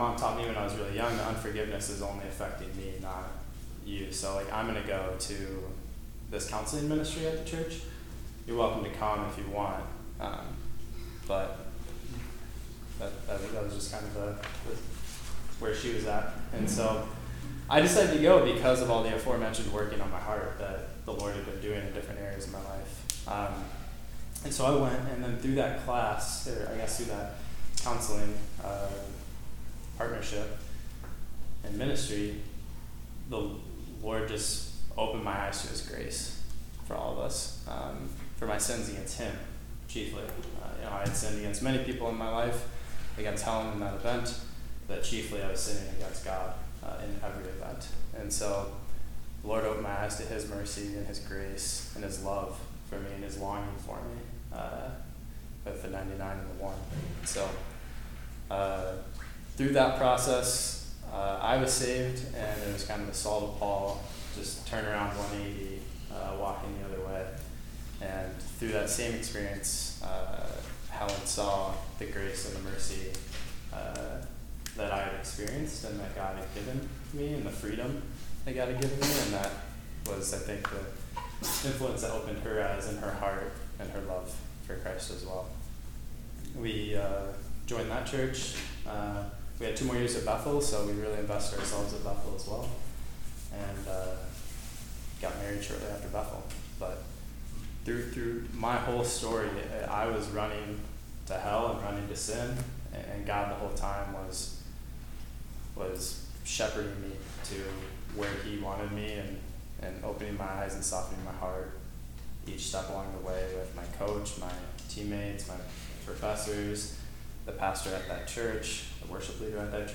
Sunday morning training hour.